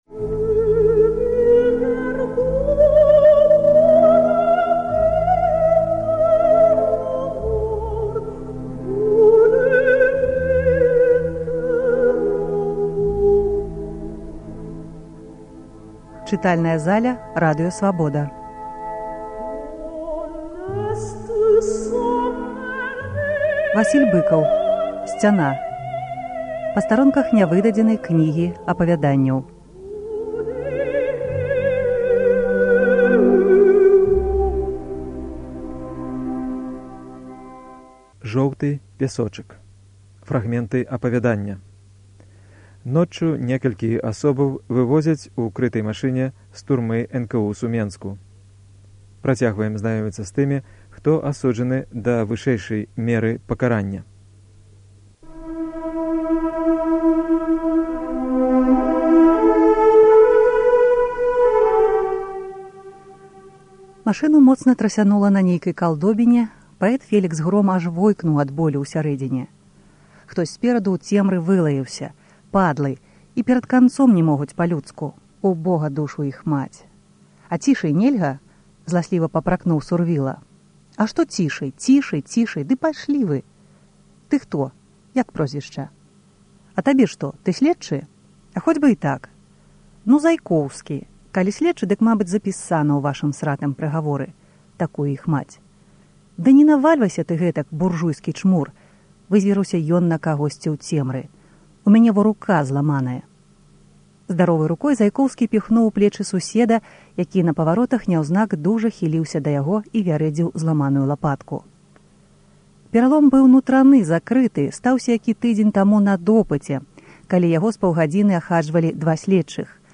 Творы мэмуарнай і мастаццкай літаратуры ў чытаньні аўтараў або журналістаў Свабоды. У перадачах бяруць удзел аўтары, героі твораў або тыя, хто іх ведаў.